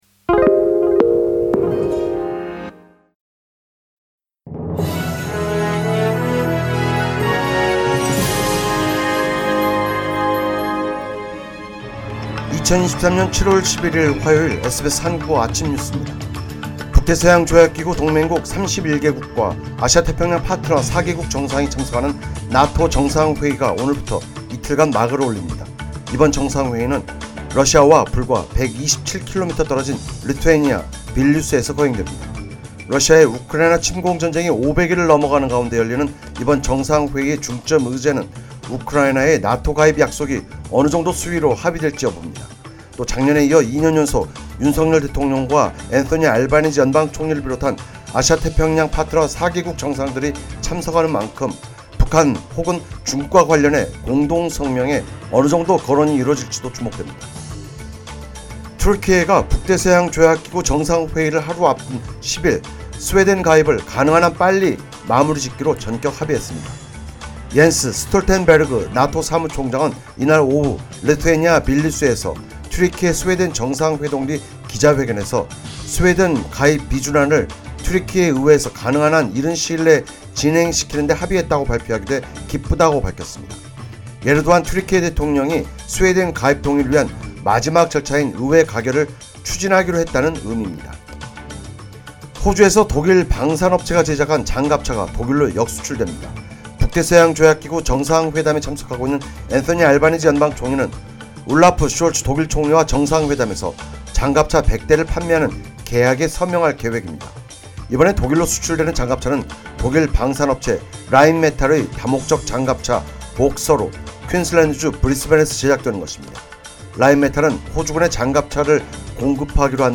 2023년 7월 11일 화요일 SBS 한국어 아침 뉴스입니다.